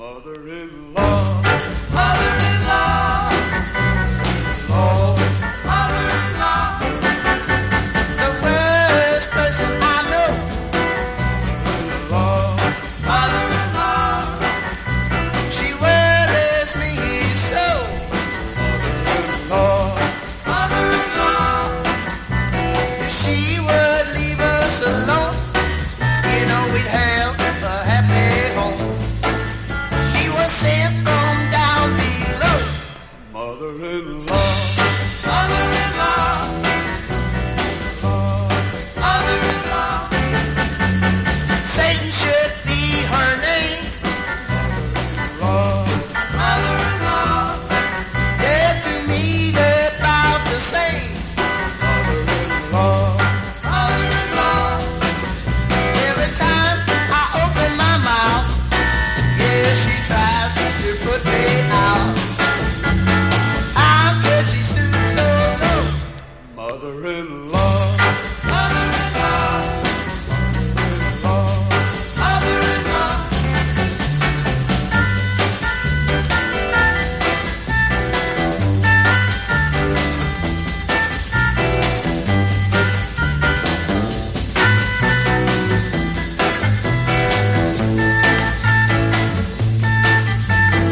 フラットロックの王様！